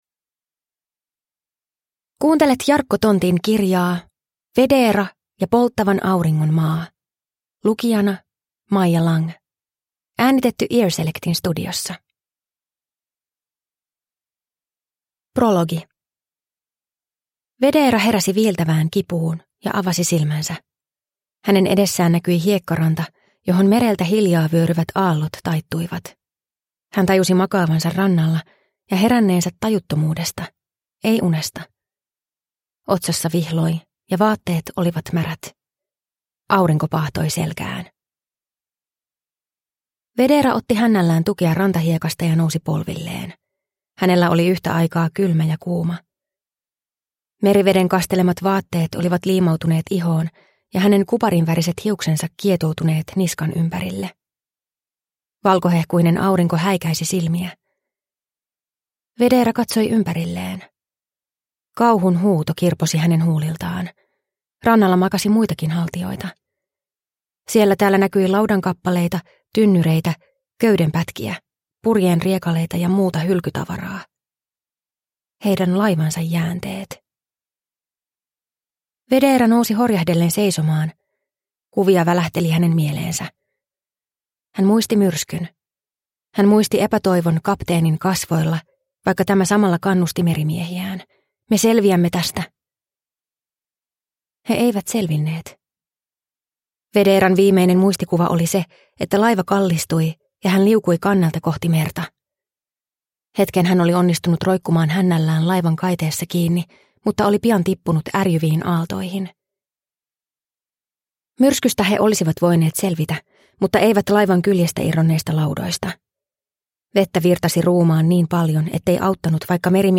Vedeera ja polttavan auringon maa – Ljudbok